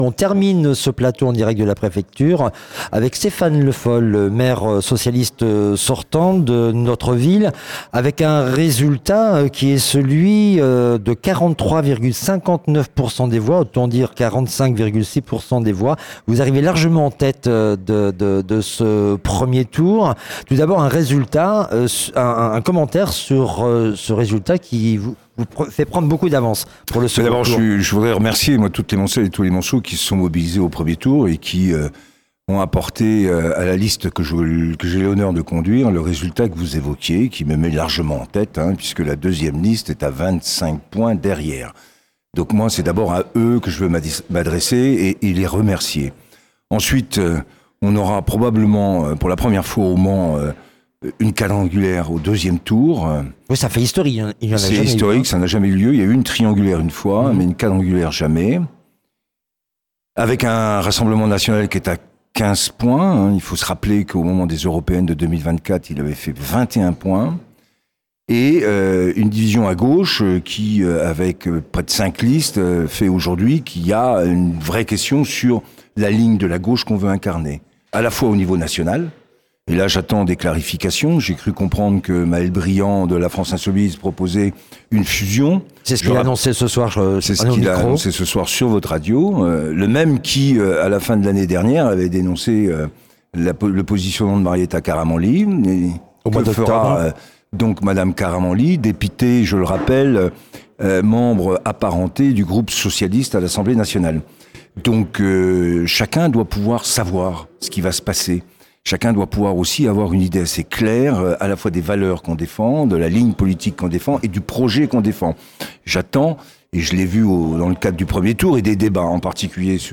soiree-electorale-du-premier-tour-stephane-le-foll.mp3